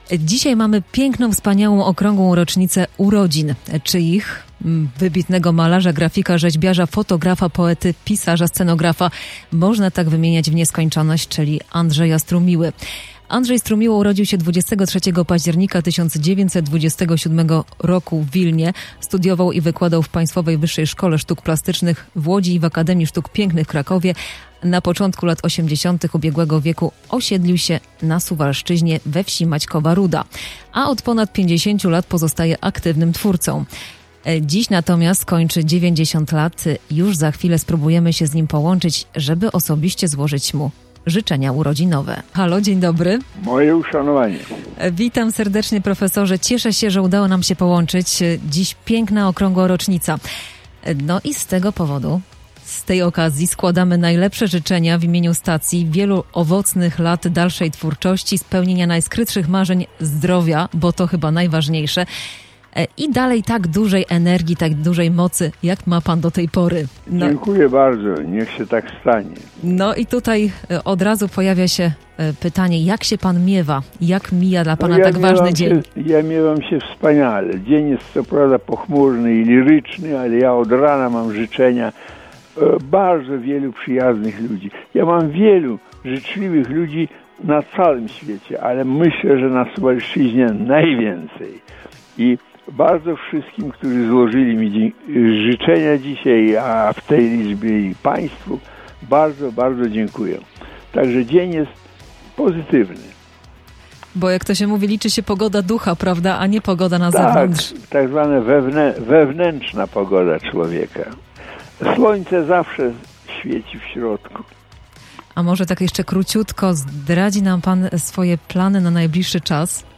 strumiłło-cała-rozmowa.mp3